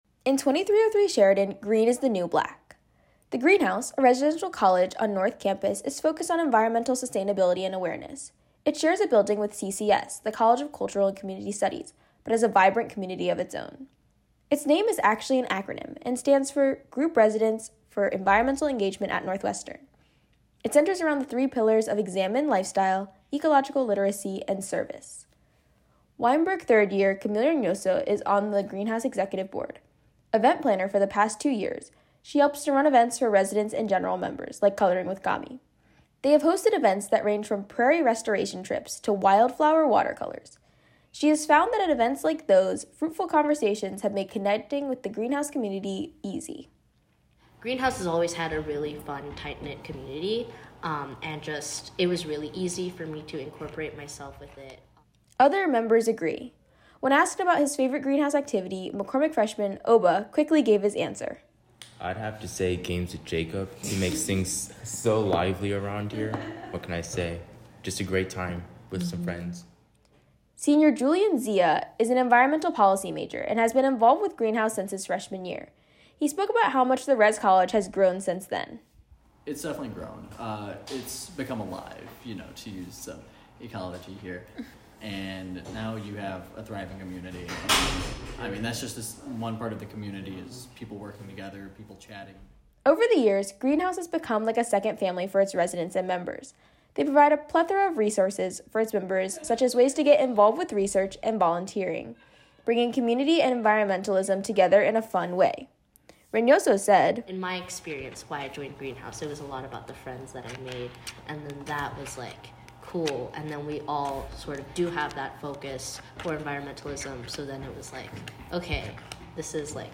This story originally aired as part of our WNUR News Touches Grass Special Broadcast.